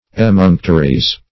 Emunctory \E*munc"to*ry\, n.; pl. Emunctories. [L. emunctorium